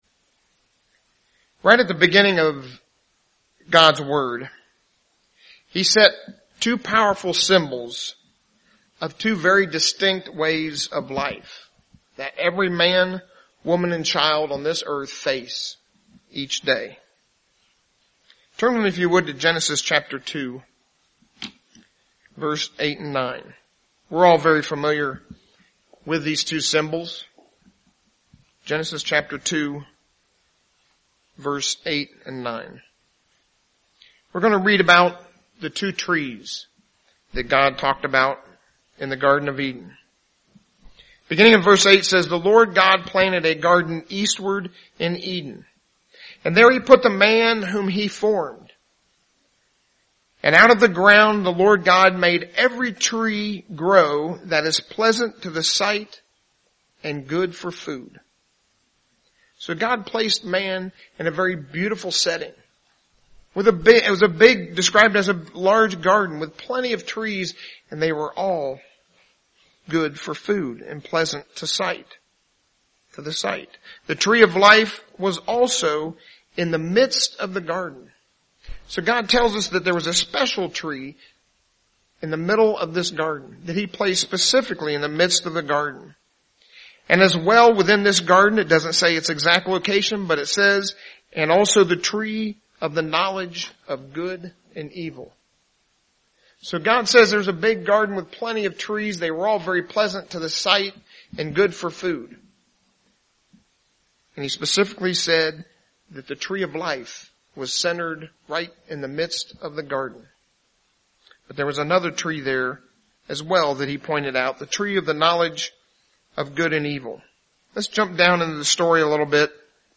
in this first of a two-part sermon.